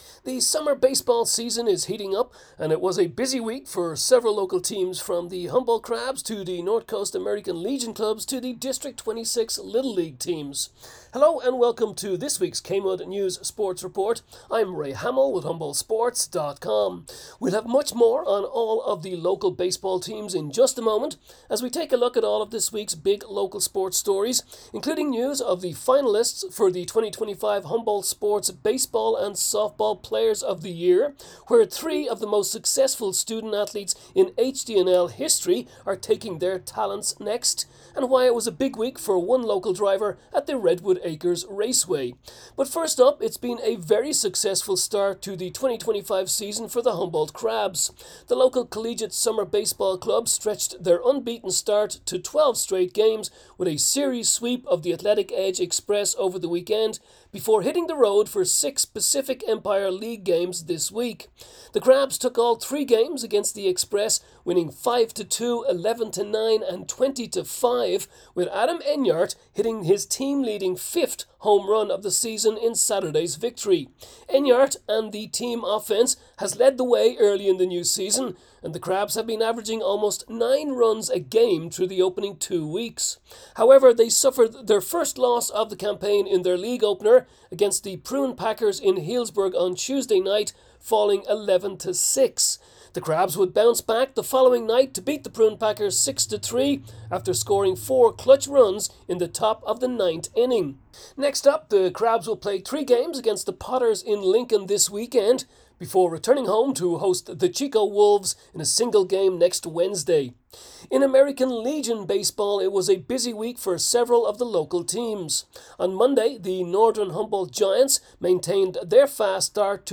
KMUD News Sports Report June 19